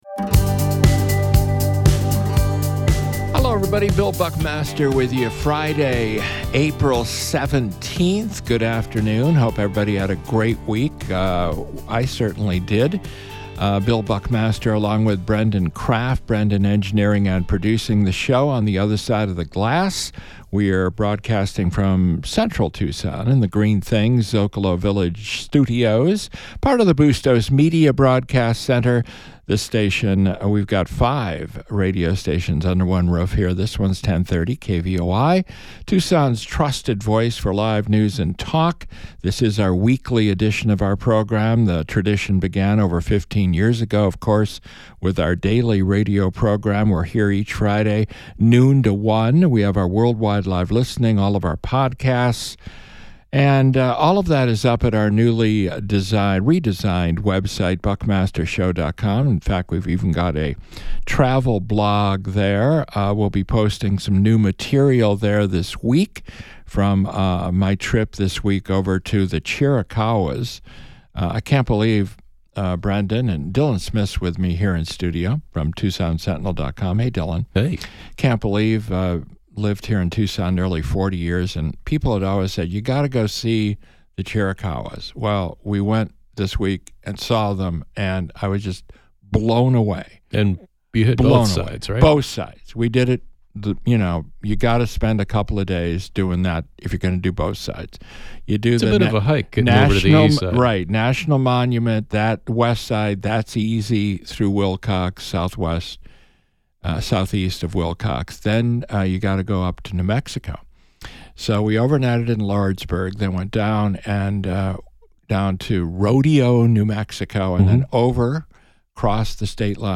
Guests include Arizona Attorney General Kris Mayes